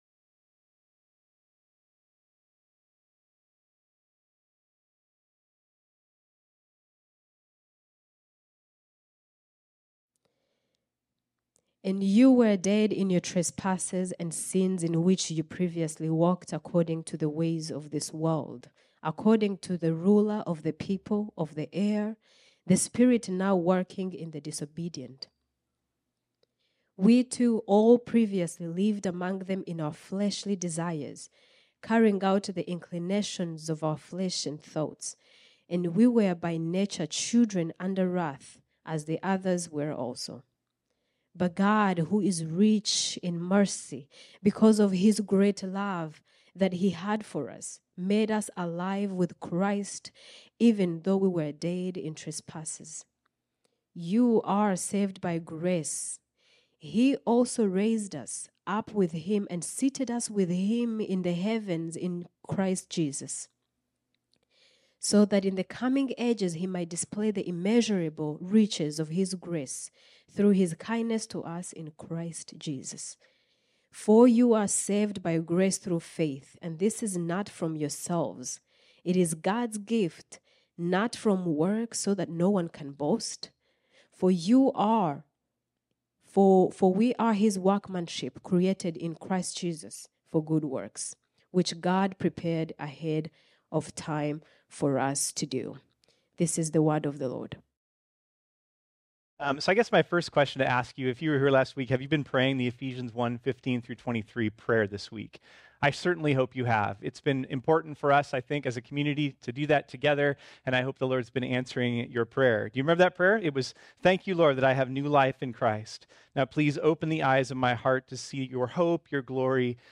This sermon was originally preached on Sunday, September 24, 2023.